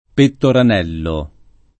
[ pettoran $ llo ]